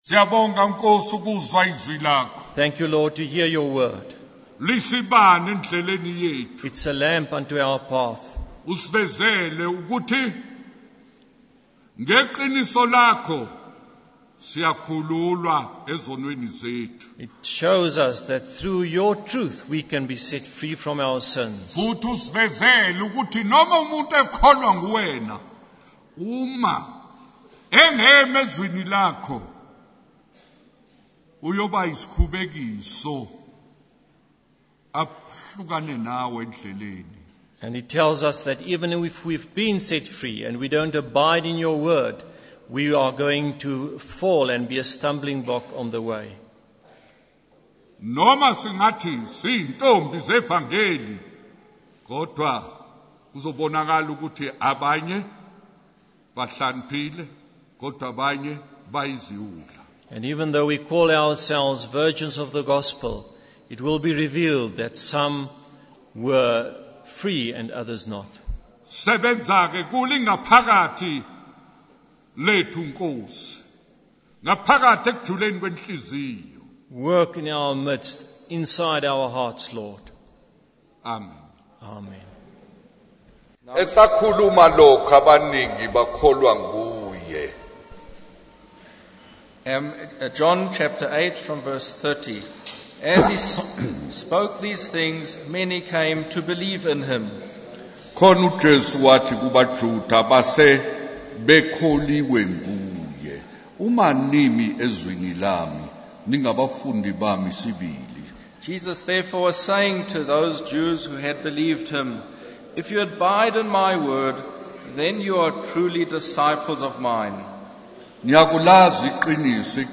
In this sermon, the preacher emphasizes the surpassing greatness of God's mighty power that works within believers. He highlights how this same power raised Jesus from the dead and is available to those who believe. The preacher also emphasizes the importance of abiding in God's word, as it is a lamp unto our path and sets us free from sin.